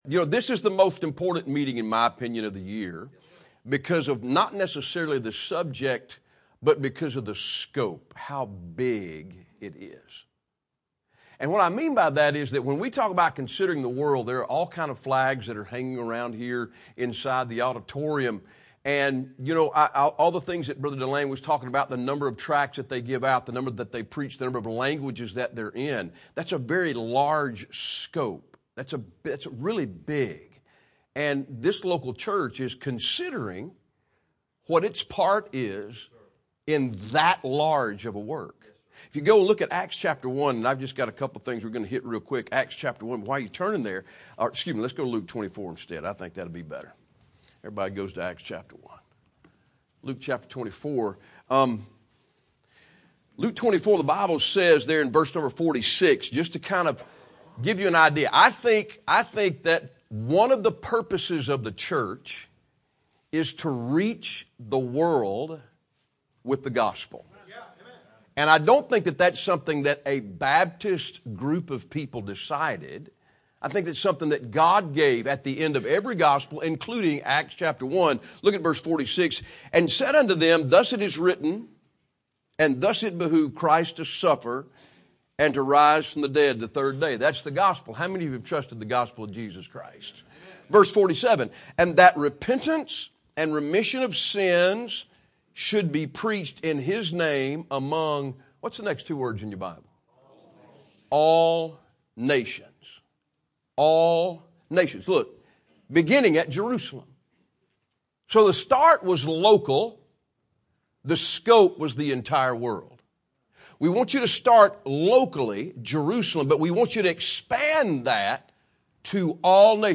Sunday School Lessons
missions conference